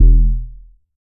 KINGSTON BAS.wav